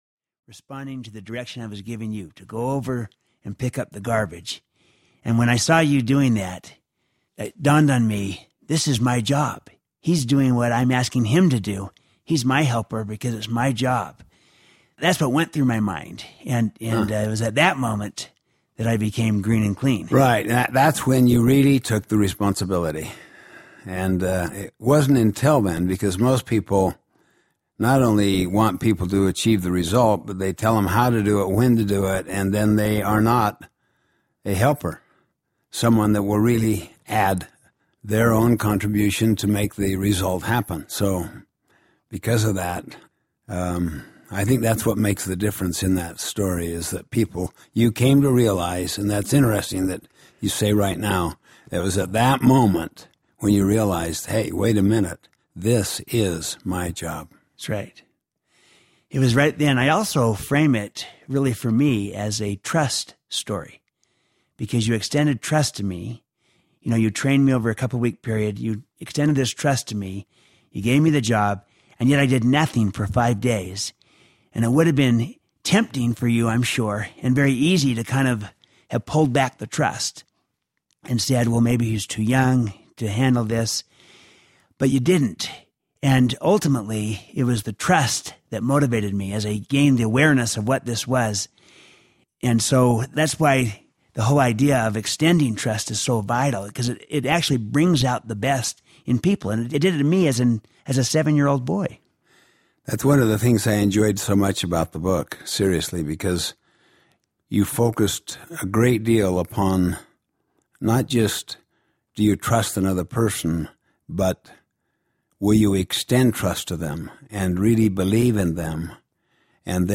A Conversation on Trust Audiobook
Narrator
Stephen R. Covey
1.1 Hrs. – Unabridged